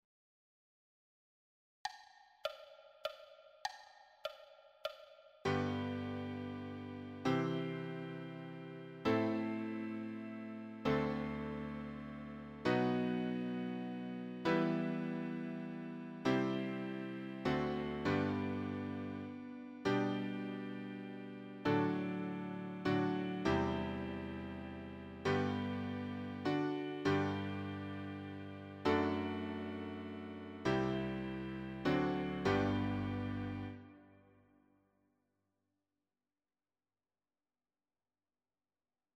Play along the piano midi recording
We-wish-you-a-merry-christmas-piano.mp3